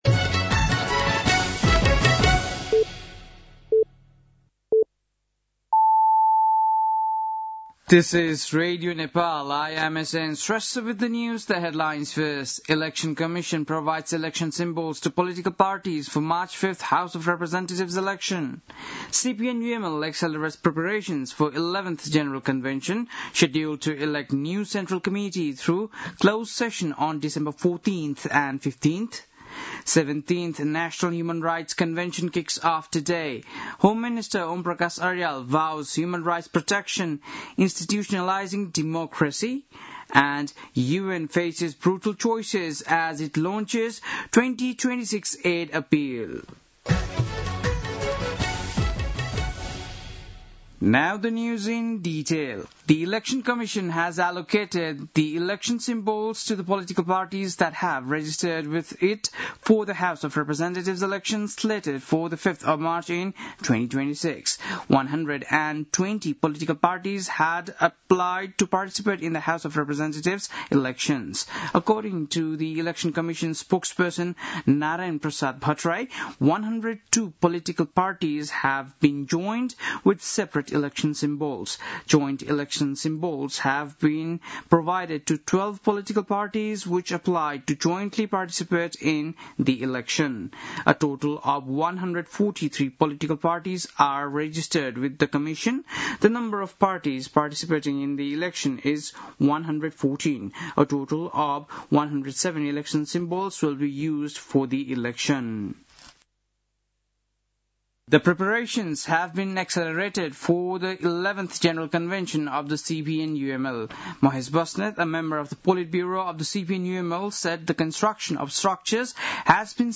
An online outlet of Nepal's national radio broadcaster
बेलुकी ८ बजेको अङ्ग्रेजी समाचार : २२ मंसिर , २०८२
8-pm-english-news-8-22.mp3